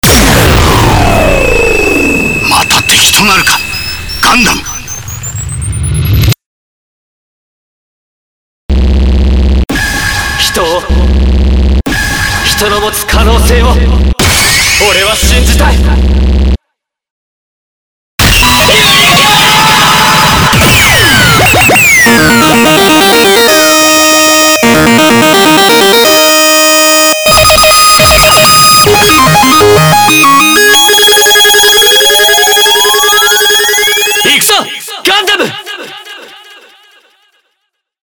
『機動戦士ガンダムUC』より3000FEVER昇格時の音声を内蔵した目覚まし時計が登場!